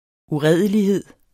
Udtale [ uˈʁεːðəliˌheðˀ ]